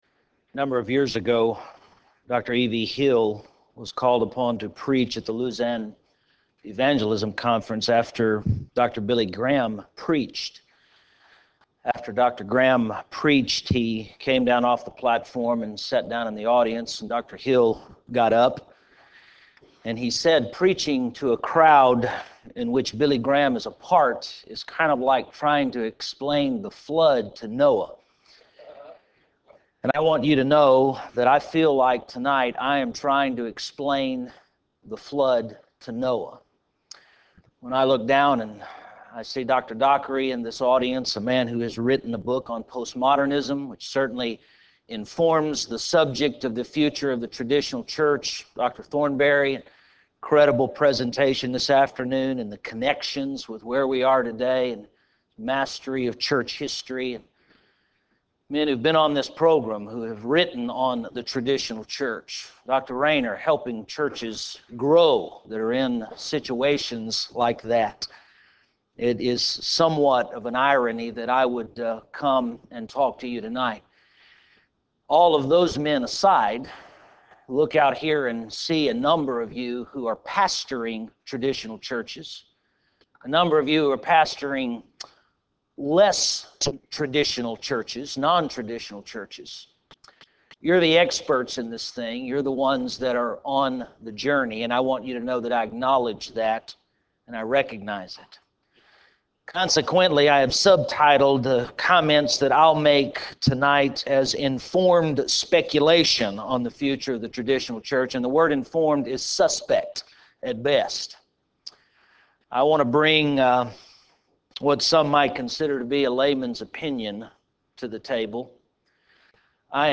Address: The Future of the Traditional Church Recording Date